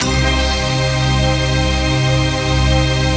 DM PAD5-5.wav